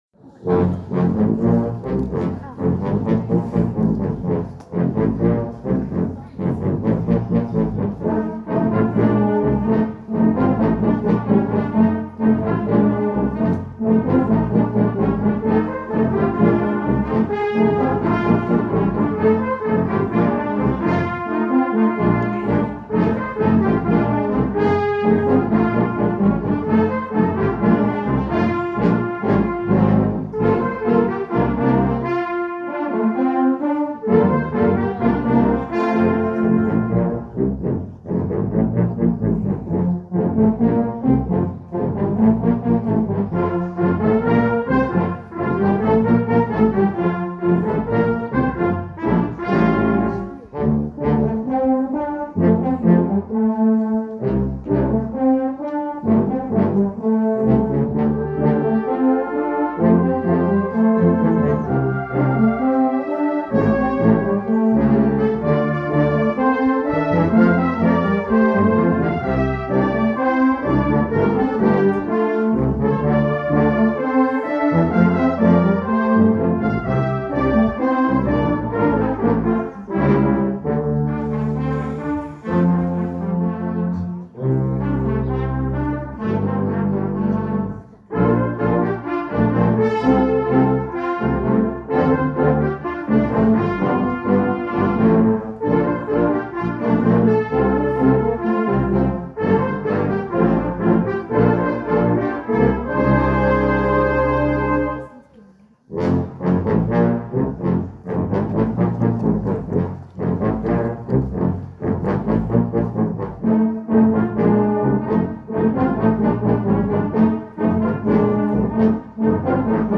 Juli 2025 – Posaunenchor Vincenzenbronn
2025 Gemeindefest Großhabersdorf, 27.07.25
Zuversichtlich wurde entschieden, den Gottesdienst dennoch im Freien auf der CVJM-Wiese zu feiern.
Für unser fetziges Eingangsstück “